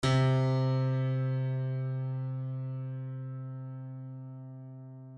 piano-sounds-dev
c2.mp3